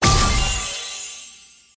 掉落弹窗.mp3